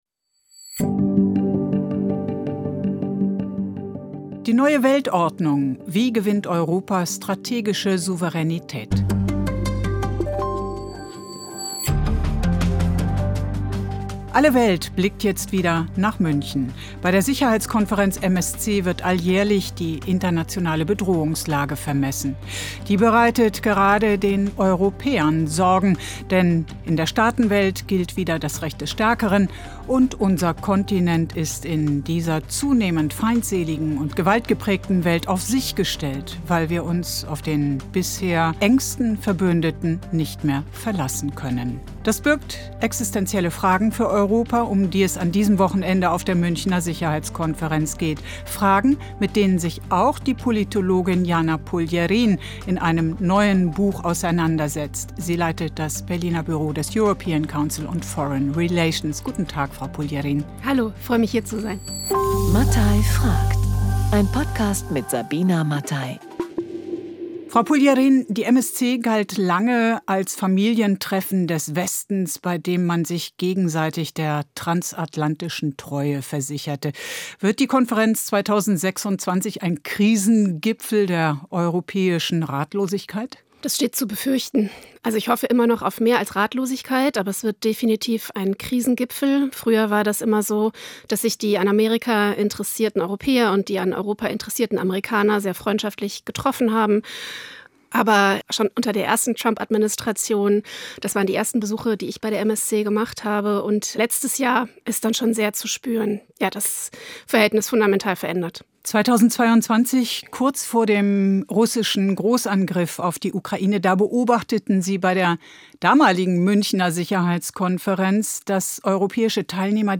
Wer verteidigt Europa? Um diese Frage geht es auch bei der Münchener Sicherheitskonferenz. Im Gespräch